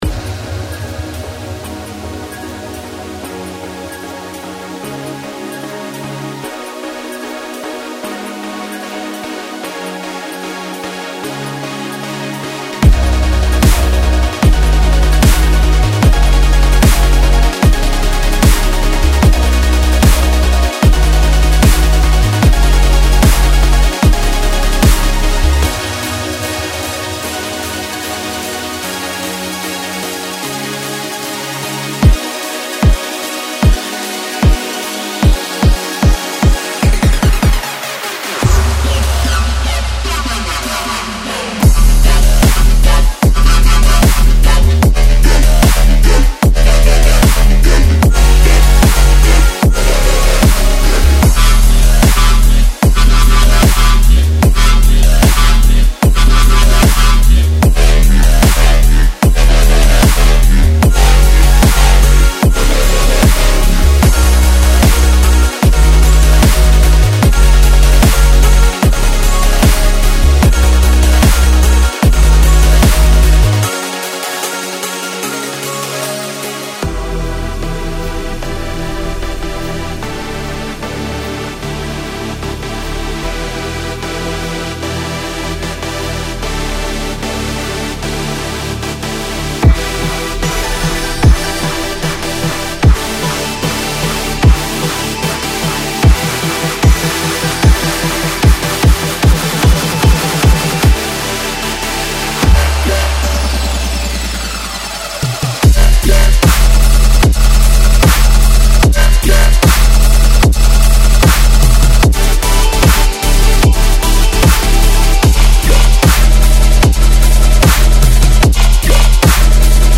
This pack features a range of driving bass, synth and drum loops with a rhythmic, dubstep vibe. Melodic vocal chops, hooks and loops are included, as well as a selection of melodic and rhythmic synth loops.